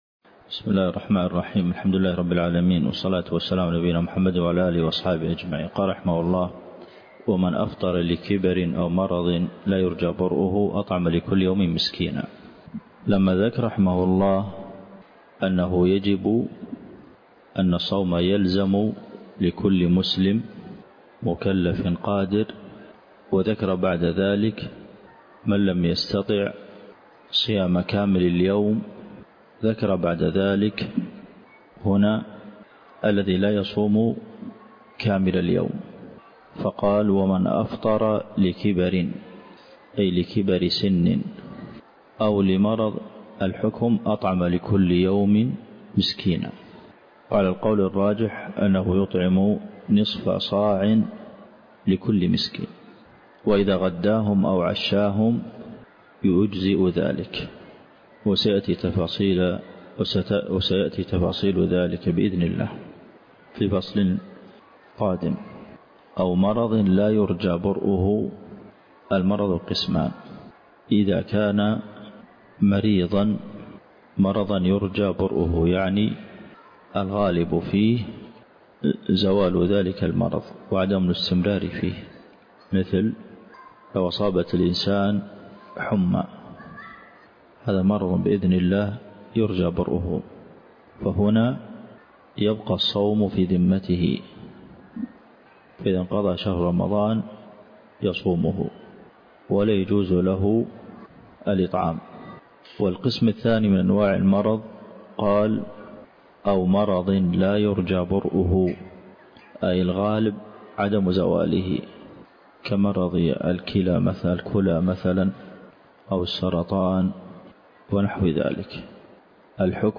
الدرس (4) شرح زاد المستقنع دورة في فقه الصيام - الشيخ عبد المحسن القاسم